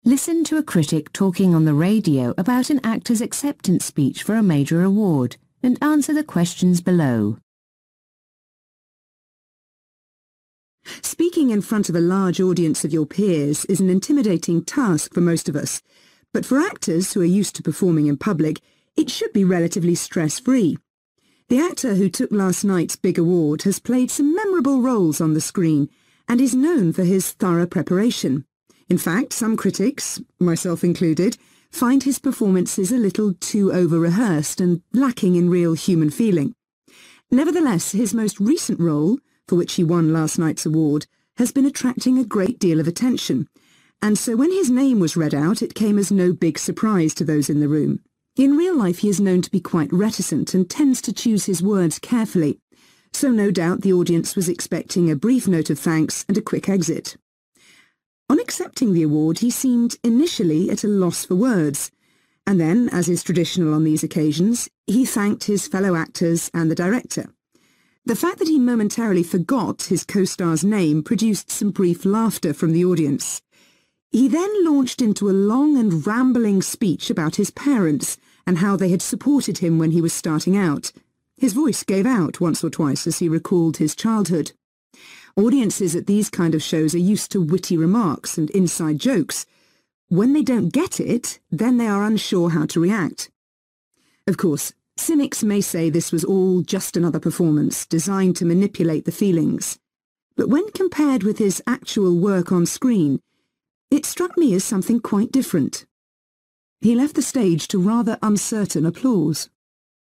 A lecturer is describing changes to a university. What makes the university special?